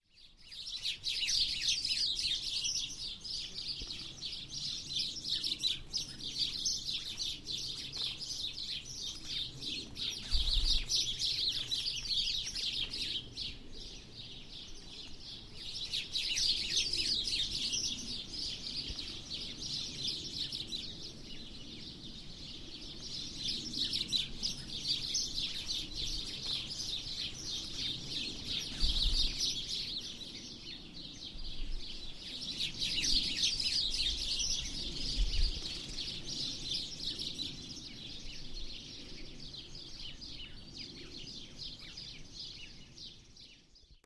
the tree was brought back into bloom using buds formed from wired headphones which produce ambient sound recorded, or live streamed from the remote environments where the work has been exhibited.
using live networked media, the sound generated switches between live microphones in france, italy and the uk.
birdsinbush.mp3